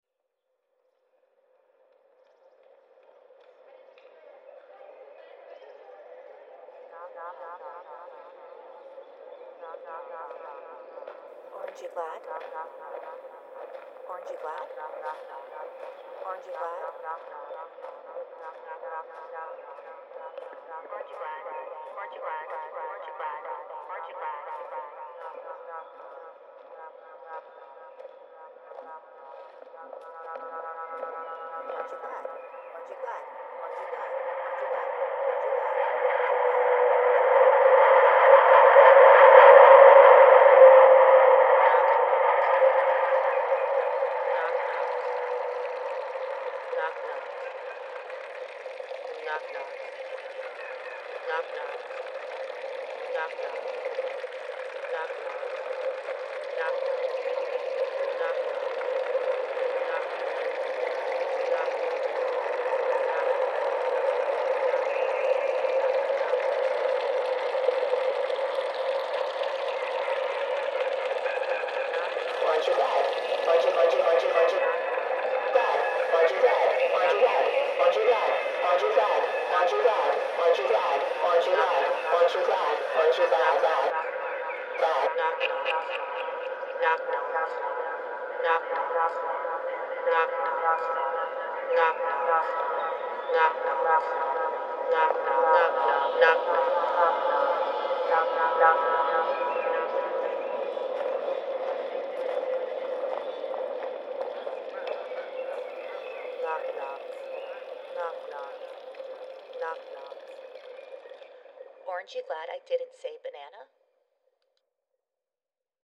using the same original field recording as source material and inspiration